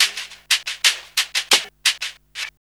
Index of /90_sSampleCDs/USB Soundscan vol.34 - Burning Grunge Hip Hop [AKAI] 1CD/Partition E/04-3HHM 89